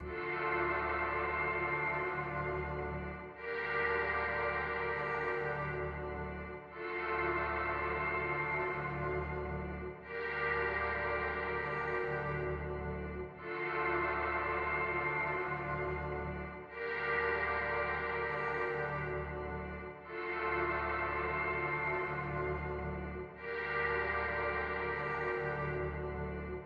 咦，诶，人
描述：一个男人在质问。
标签： 表达 男性 HAE 问题 男人 呵呵 什么
声道立体声